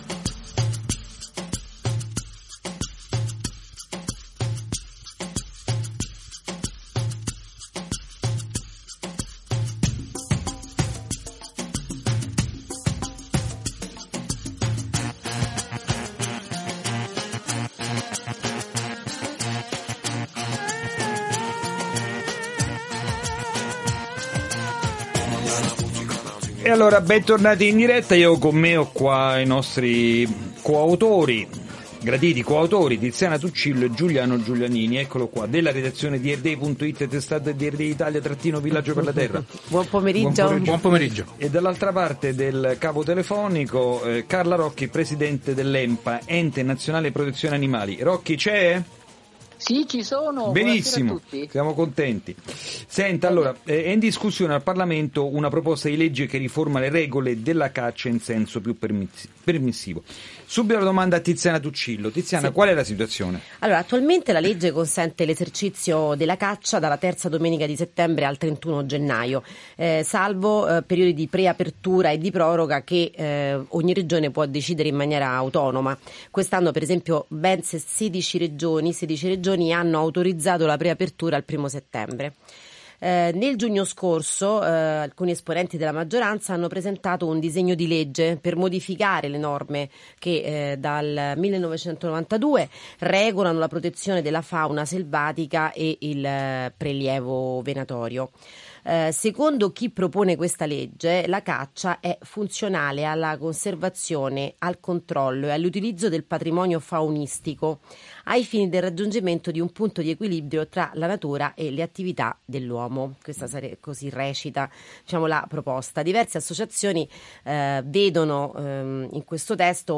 Ne abbiamo parlato in “Ecosistema”, la rubrica radiofonica settimanale di Earth Day Italia trasmessa da Radio Vaticana, con Carla Rocchi, presidente dell’ENPA Ente Nazionale Protezione Animali.